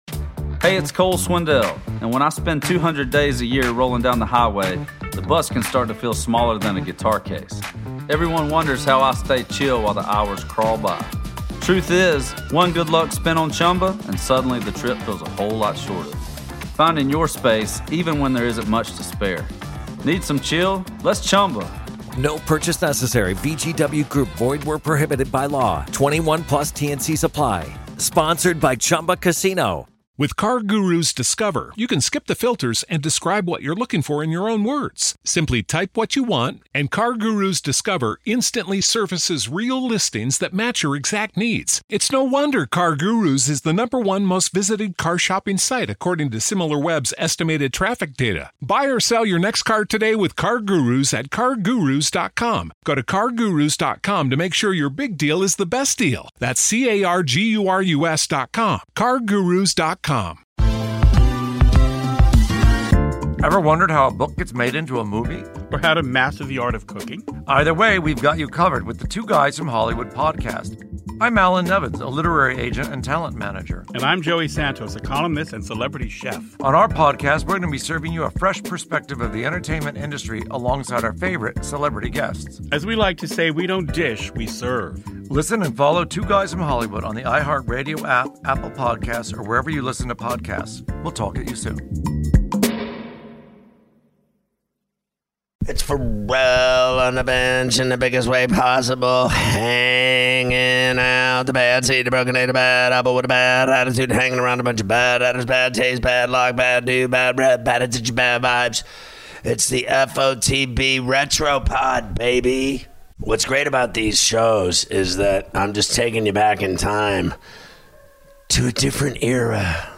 Scott Ferrall listens back to one of his old radio shows from back in June of 1997